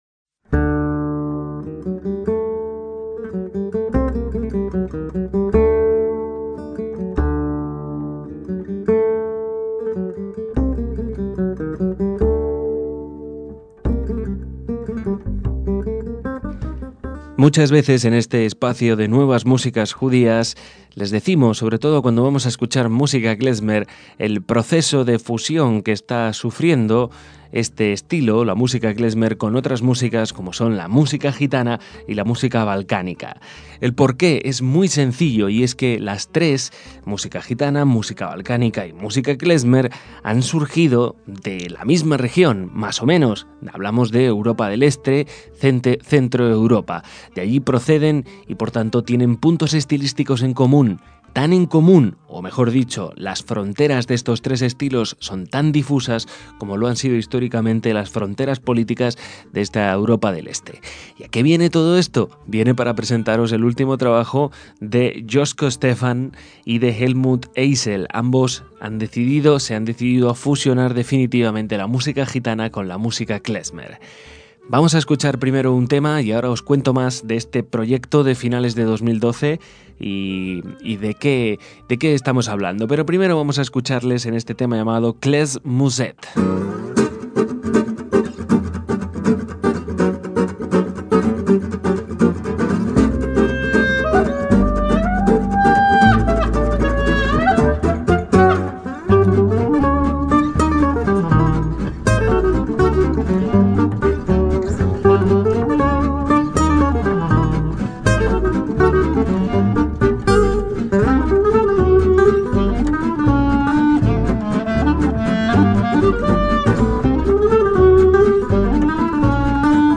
La combinación del swing gitano y la música klezmer
guitarra
clarinete
guitarra rítmica
contrabajo